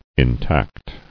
[in·tact]